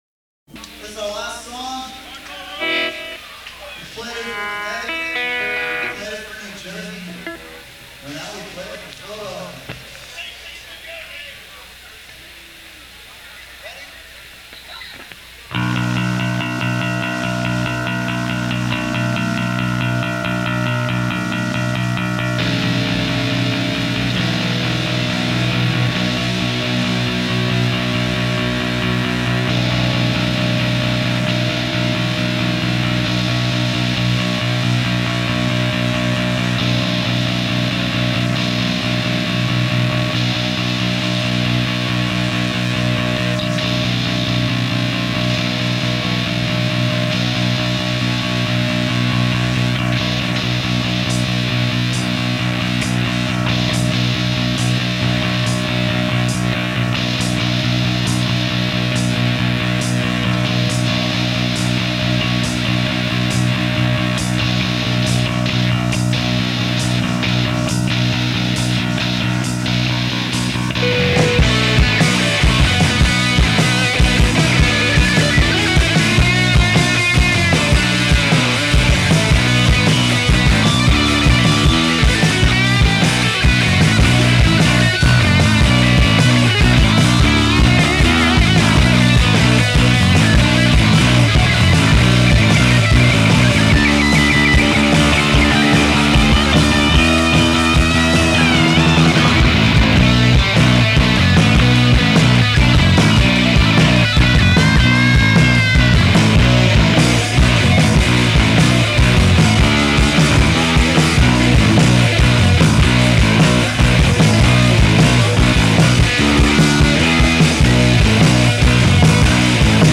Sound quality is very good.